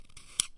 WAR 'n' BATTLE声音 模拟和实验 " 00792长刀刃3
描述：刀刃的声音可作为战斗的声音使用，通过变焦H2录制
声道立体声